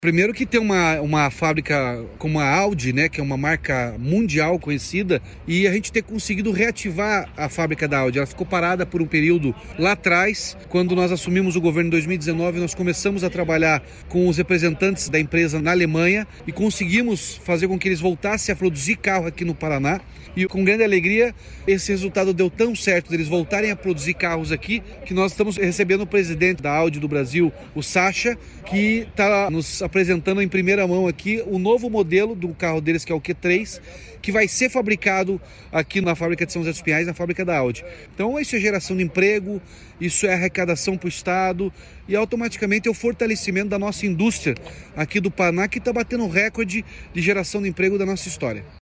Sonora do governador Ratinho Junior sobre o novo veículo produzido pela Audi em São José dos Pinhais